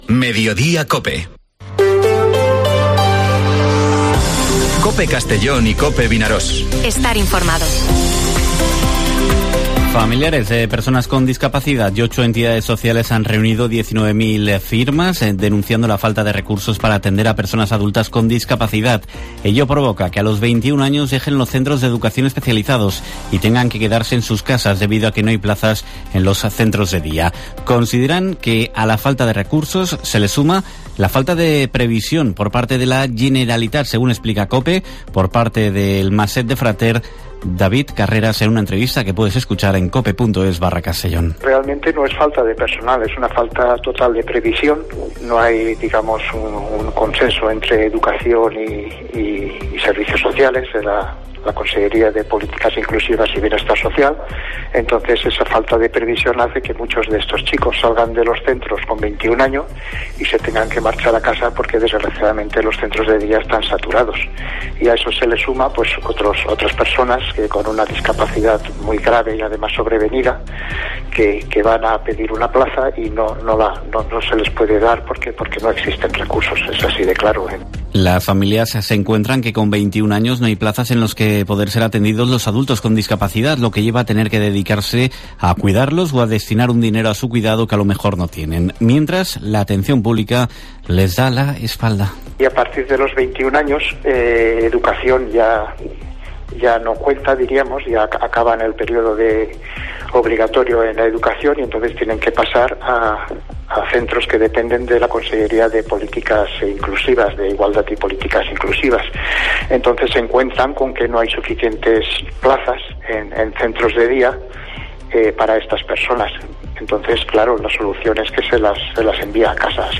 Informativo Mediodía COPE en la provincia de Castellón (09/02/2023)